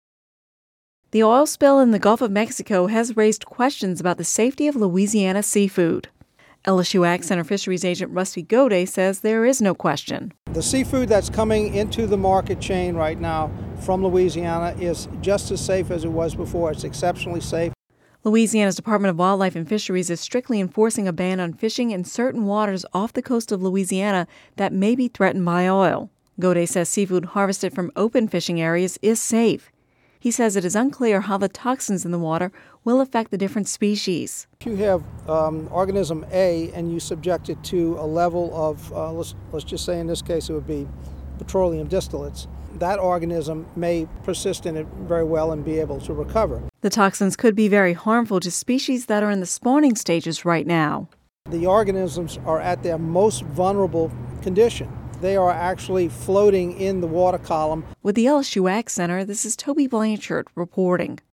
(Radio News 06/07/10) The oil spill in the Gulf of Mexico has raised questions about the safety of Louisiana’s seafood.